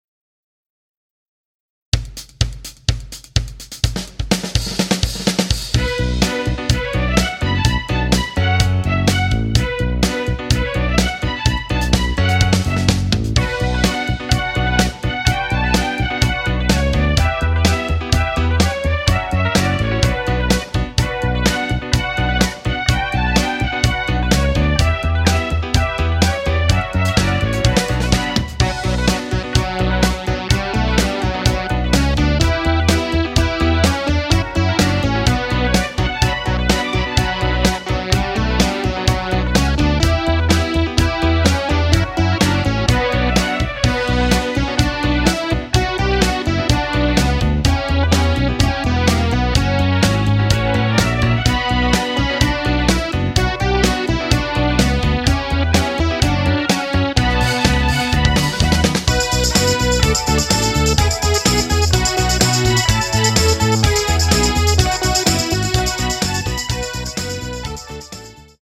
Square Dance Music
(Patter)